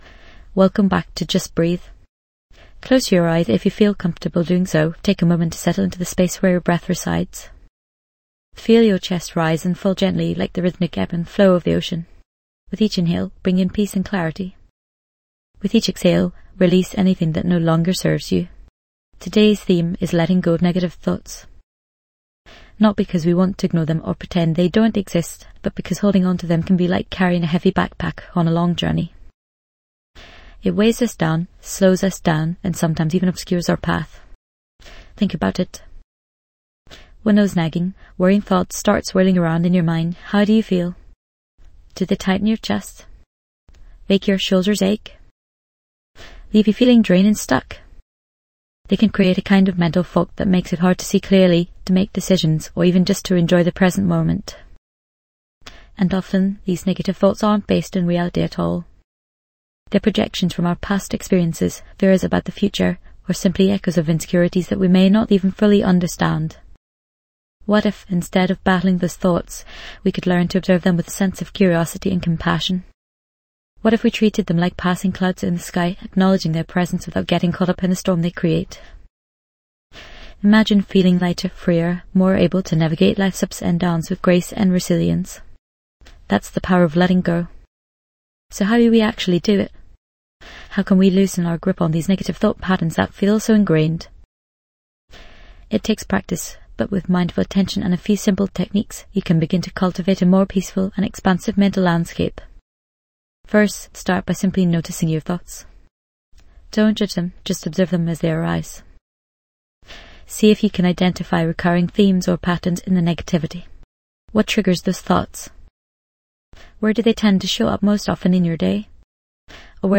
Dive deep into a powerful guided meditation designed to help you let go of negative thoughts and embrace peace. This immersive session will guide you through visualization techniques that promote mental clarity, self-compassion, and emotional release.
This podcast is created with the help of advanced AI to deliver thoughtful affirmations and positive messages just for you.